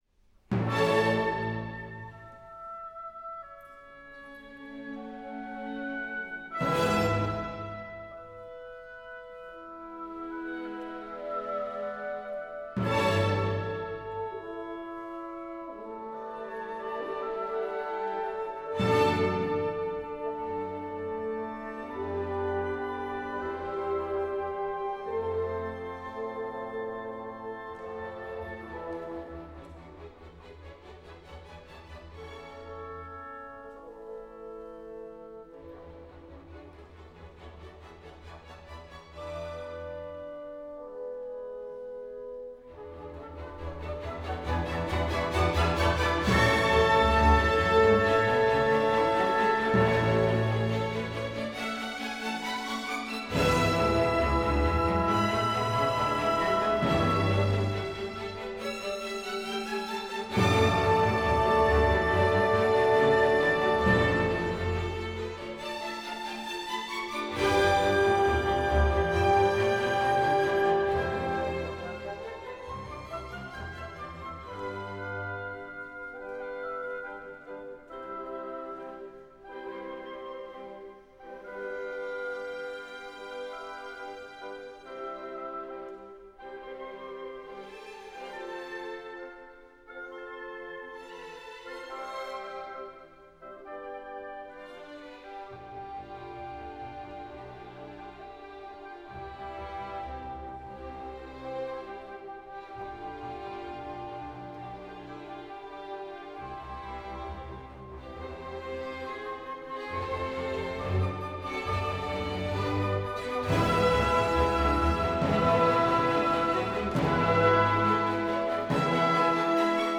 Εθνική Συμφωνική Ορχήστρα – Μέγαρο Μουσικής Αθηνών – Τετάρτη 26 Μαρτίου 2025
Beethoven Ludwig van: Συμφωνία αρ.7 σε Λα μείζονα, Op.92 [38’]